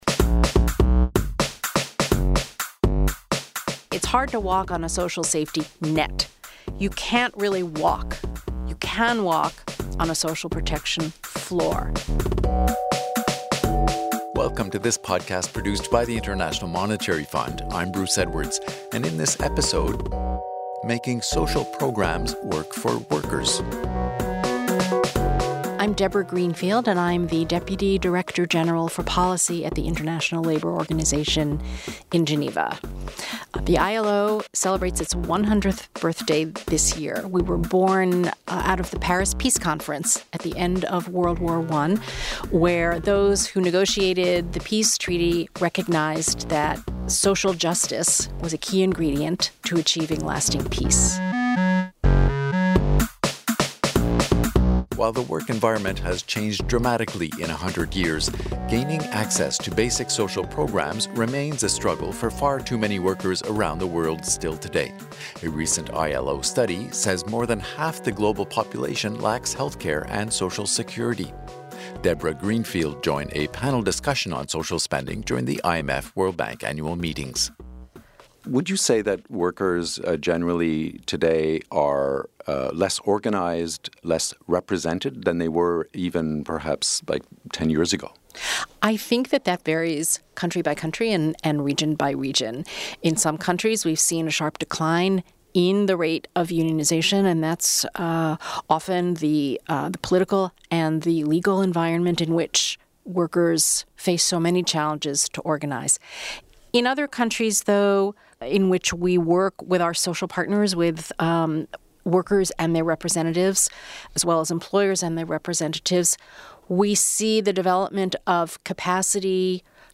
Investing in social programs can soften the blow of inequalities and foster more stable societies. In this podcast, we speak with Deborah Greenfield, Deputy Director General for Policy at the International Labour Organization, which was formed one hundred years ago out of the Paris Peace Conference at the end of World War 1.